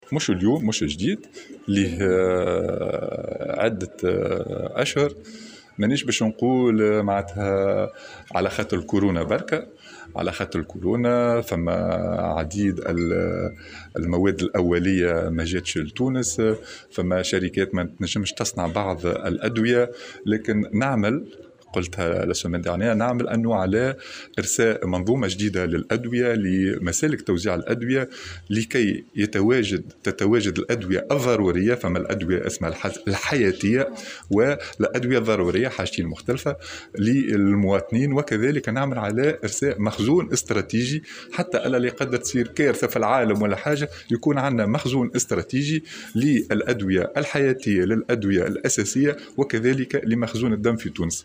وأرجع الوزير، في تصريح للجوهرة أف أم، على هامش ورشة عمل بمناسبة الاحتفال بالأسبوع العالمي للرضاعة الطبيعية، النقص الحاصل على مستوى الأدوية، إلى تداعيات جائحة كورونا، إضافة إلى عدم توفر بعض المواد الأولية، ما تسبب في تأخر عدد من الشركات في تونس في صنع بعض الأدوية .